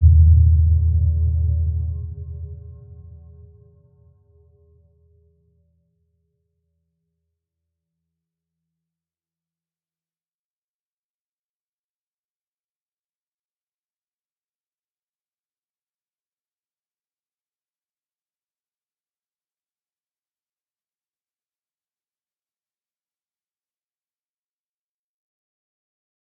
Round-Bell-E2-f.wav